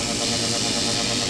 Index of /server/sound/weapons/tfa_cso/stunrifle
shootc.wav